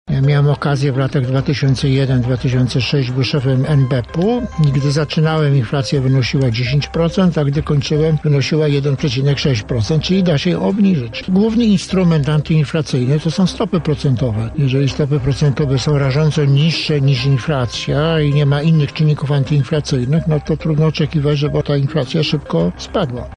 Tak stwierdził w Porannej Rozmowie Radia Centrum dzisiejszy gość prof. Leszek Balcerowicz, polityk i ekonomista, wicepremier i minister finansów w latach 1989-1991 oraz 1997-2000; prezes Narodowego Banku Polskiego w latach 2001-2007.